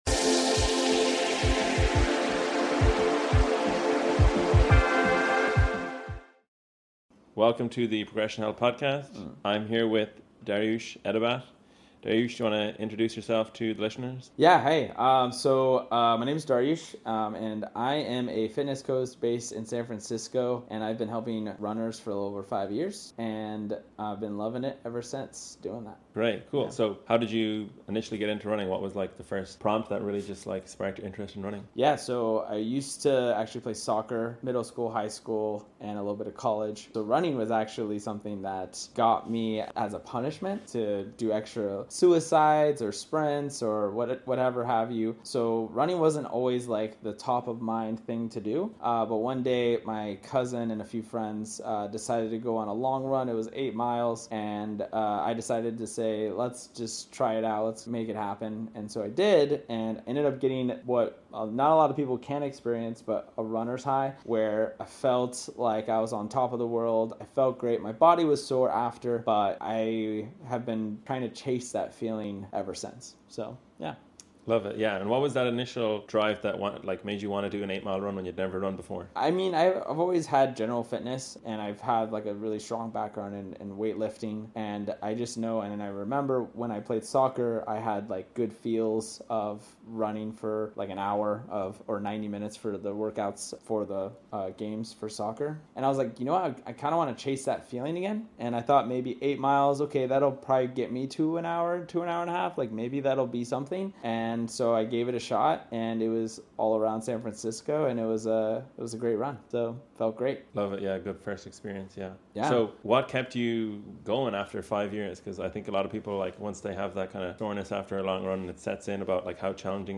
Whether you're just starting or training for your next race, this conversation is packed with valuable **running tips** and **training strategies** to help you improve your endurance and enjoy running more!